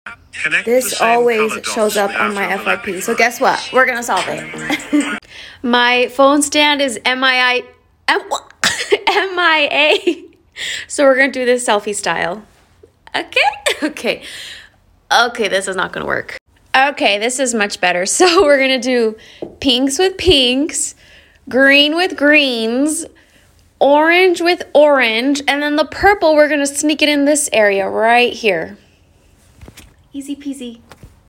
Sounded a bit Australian there sound effects free download